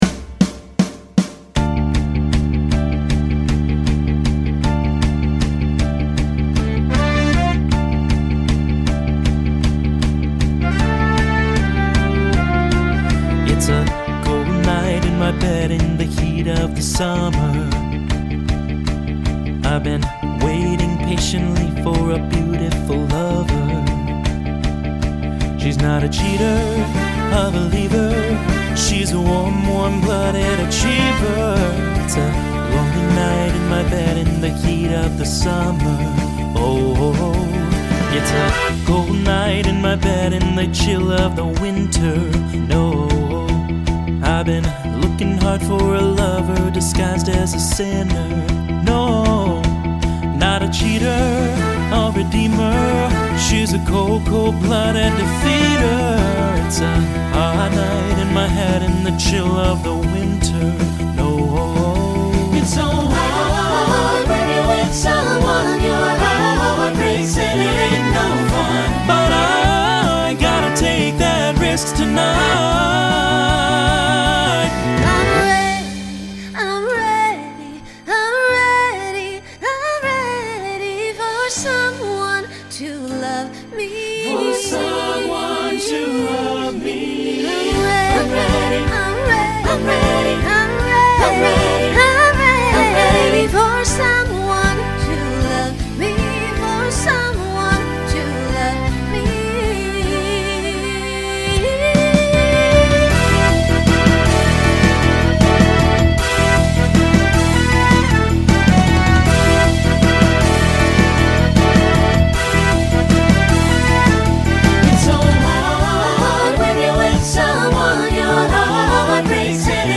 Genre Pop/Dance
Transition Voicing SATB